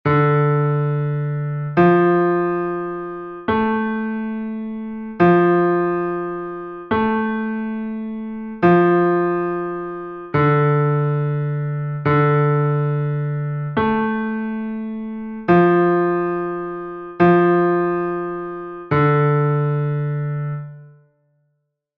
note recognition exercise 4
4_D_F_A_fa4a_12_notas.mp3